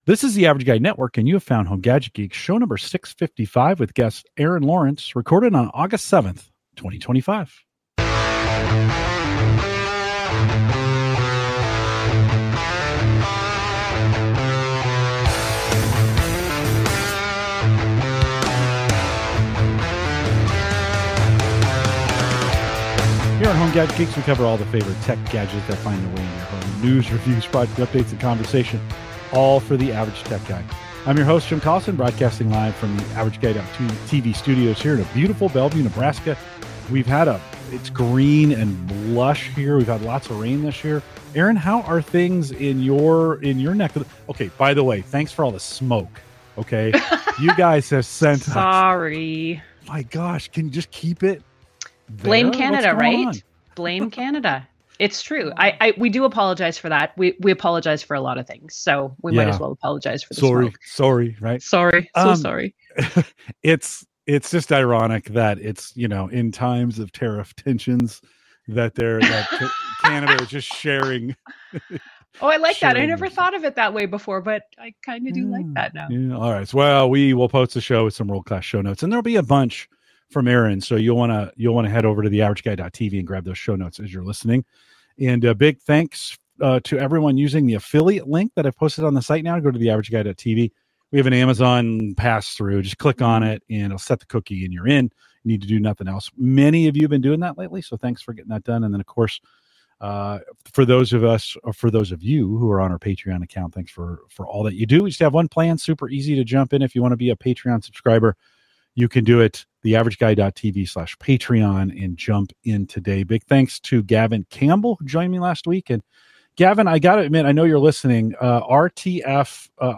wide-ranging conversation on home, lawn, and automotive gadgets